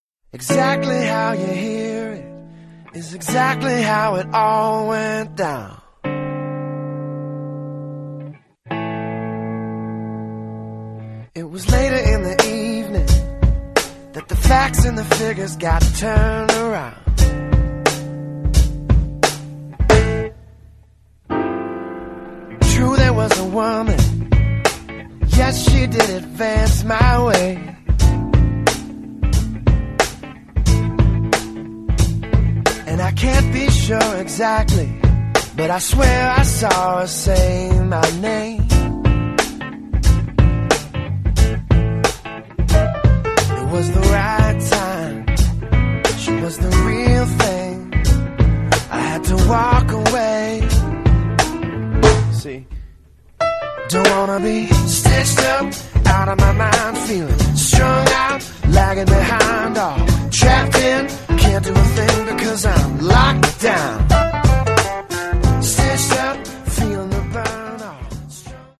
Organ, Piano, Keyboards
Guitar, Vocals